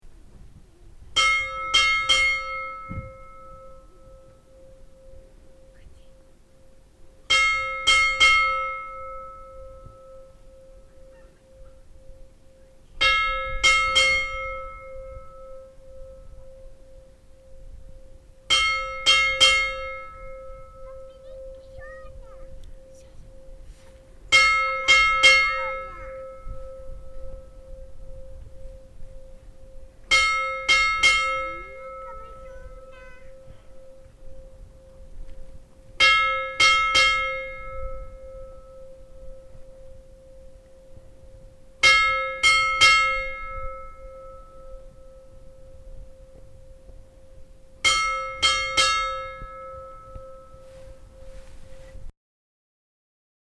Í kirkjunni eru tvær kirkjuklukkur sem staðsettar eru á kirkjulofti ofan við innganginn. Engar þverspýtur eða kaðlar eru festar við rambaldana og því sveiflast þær ekki.
Klukkunum er því hringt með því einfaldlega að slá kólfunum í klukkurnar.
Stærri klukkan Dagverðarneskirkja.
dagverdarneskirkja_staerri.mp3